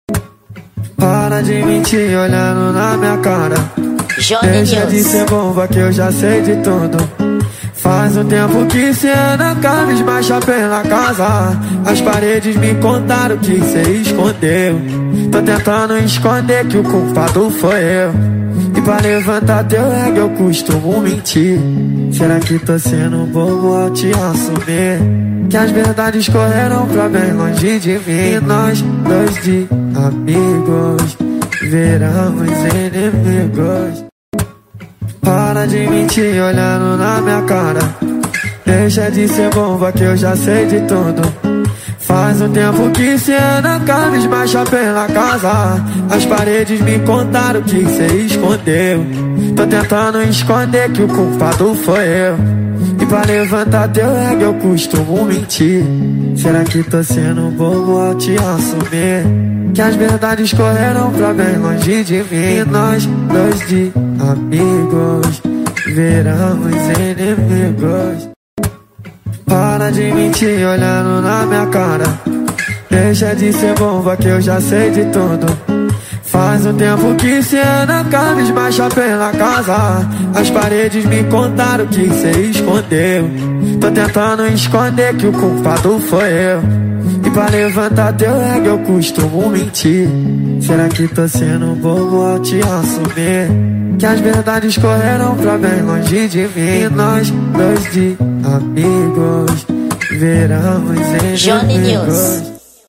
Gênero: Acústico